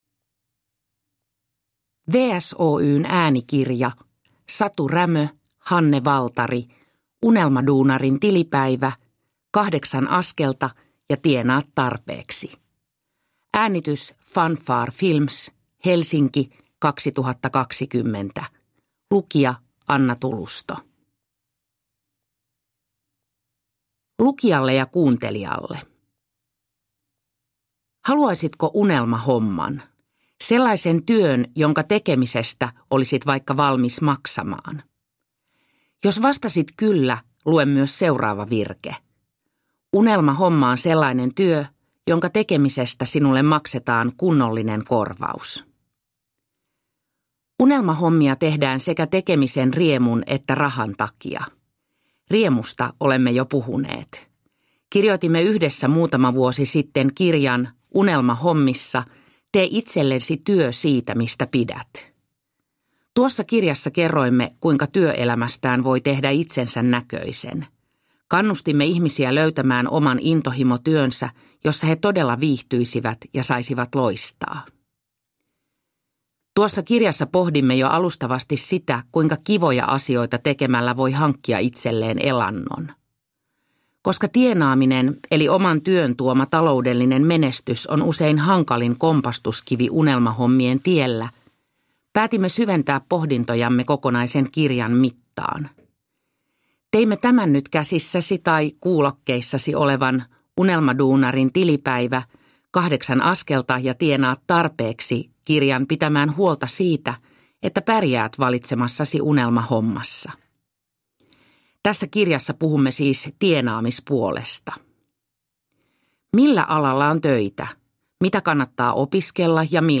Unelmaduunarin tilipäivä – Ljudbok – Laddas ner